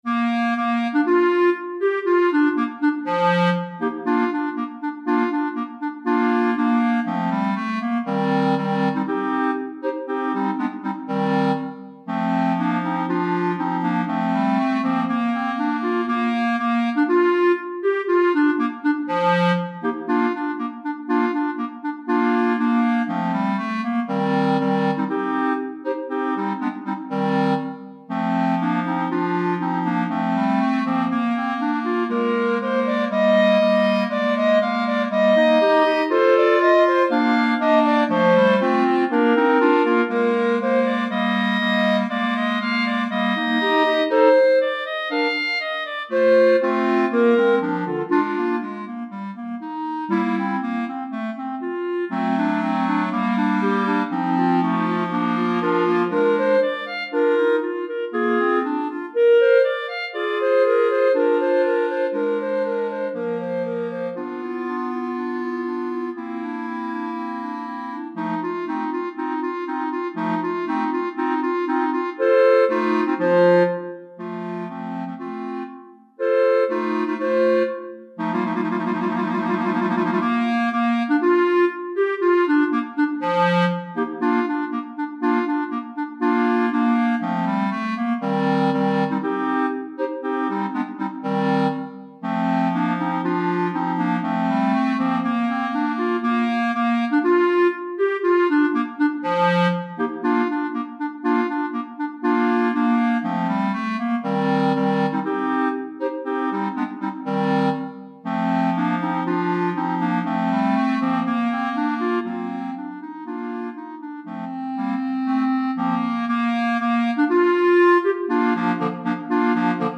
Répertoire pour Clarinette - 3 Clarinettes en Sib